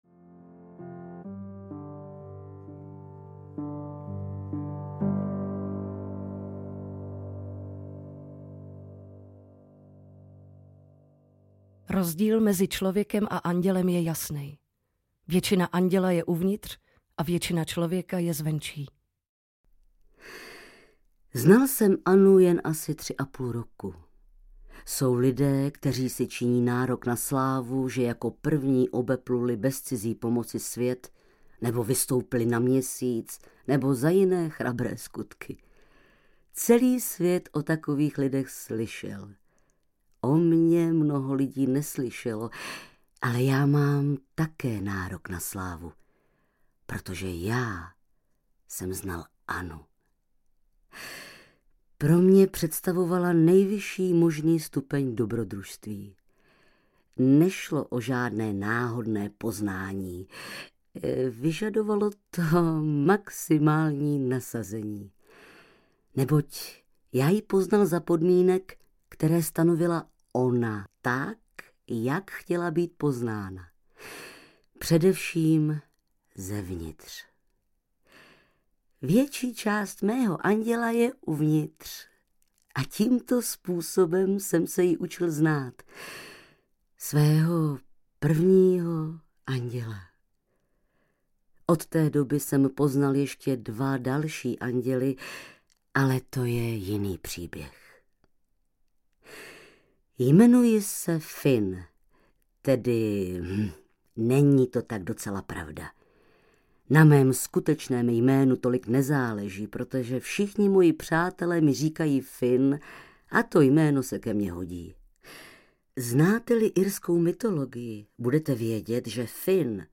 Pane Bůh, tady Anna audiokniha
Ukázka z knihy
• InterpretAneta Langerová